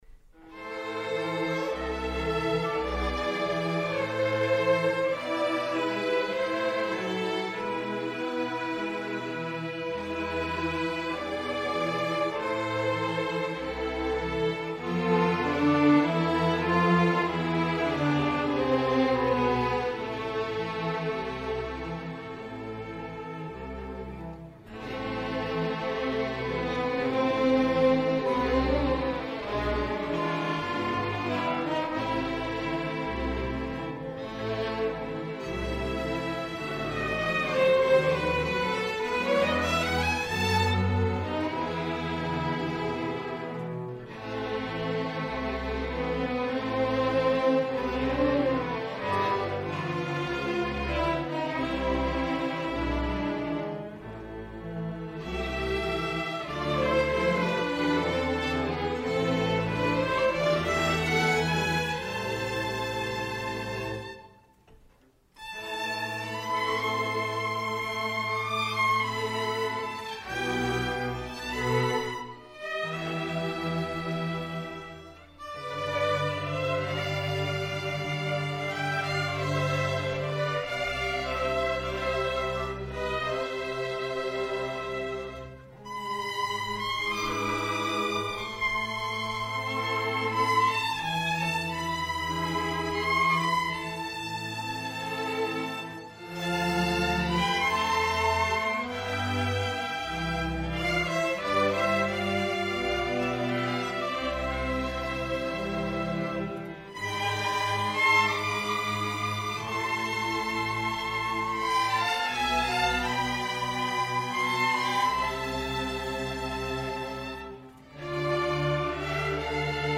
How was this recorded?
Spring 2017 Concert